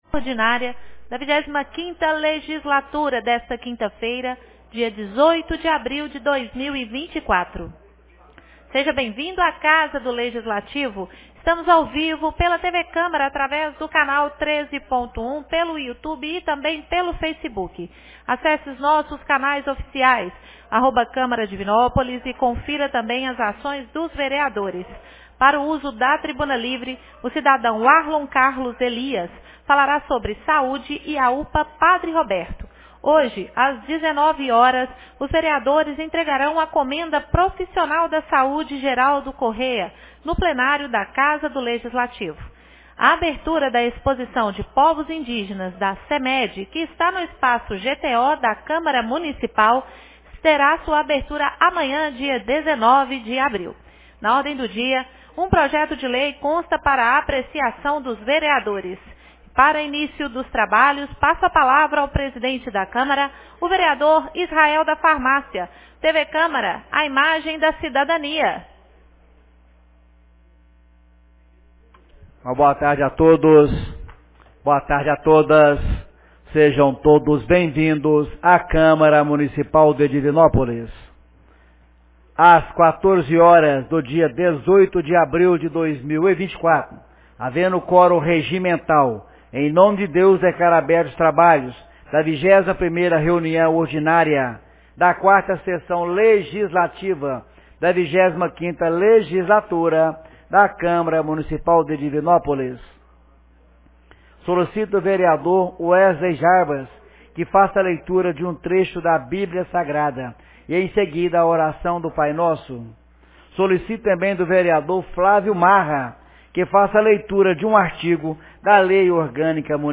21ª Reunião Ordinária 18 de abril de 2024